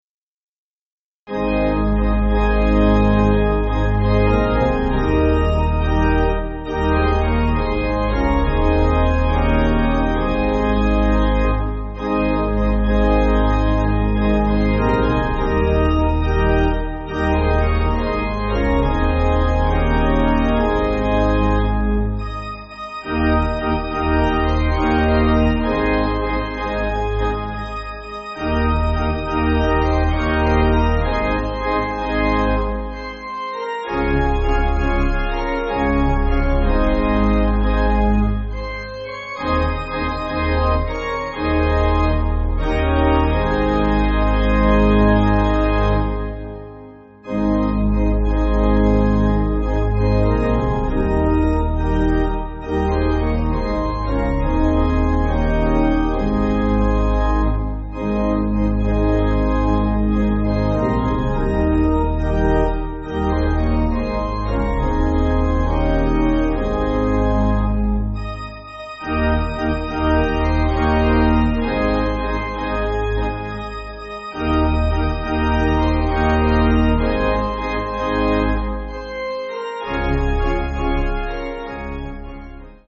Organ
(CM)   3/Ab